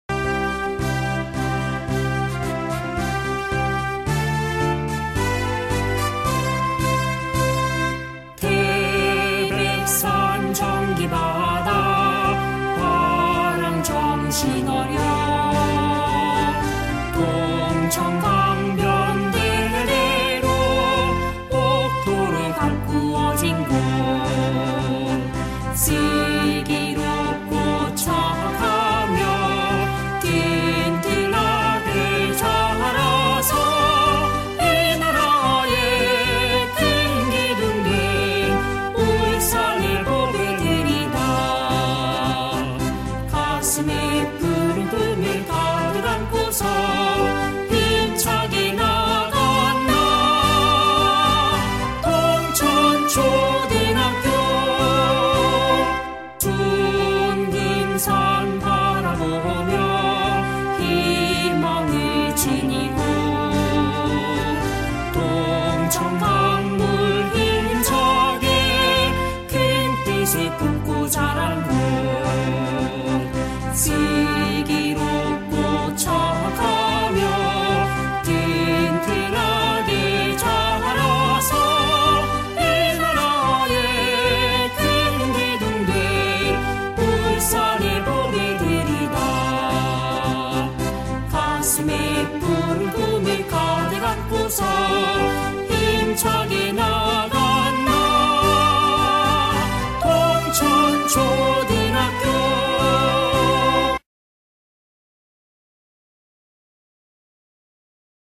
동천초등학교 교가 음원 :울산교육디지털박물관